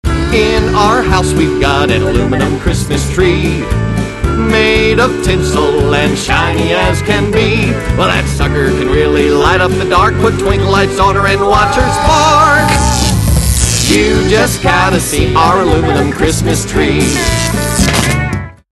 This hilarious collection of novelty songs includes